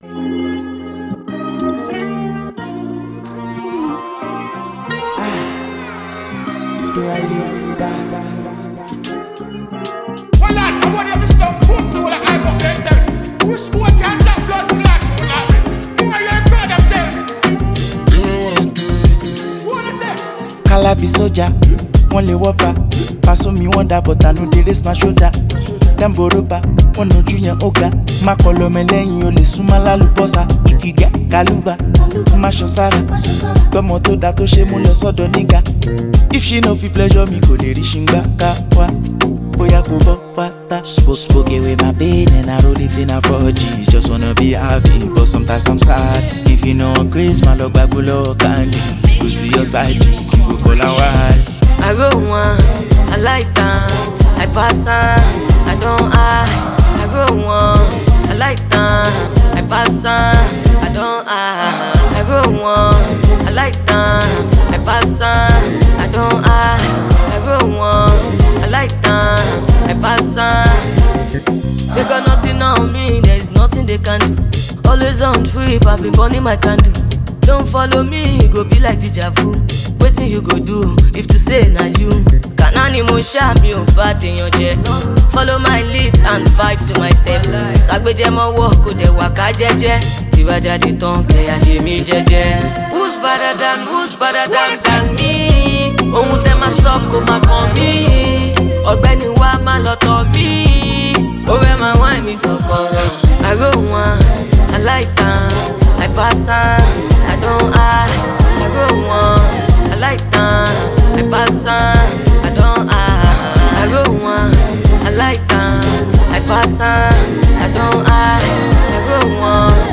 street-hop